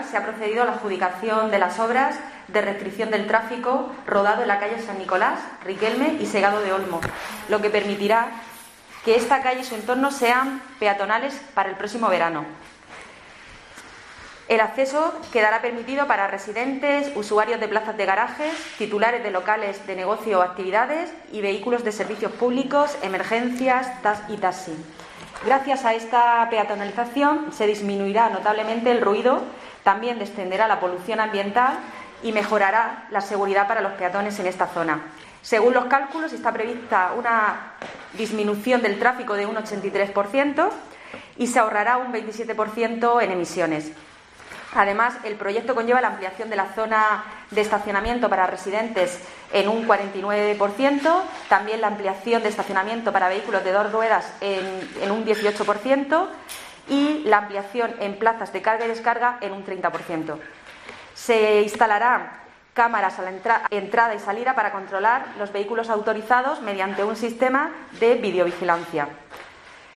Paqui Pérez, portavoz del Ayuntamiento de Murcia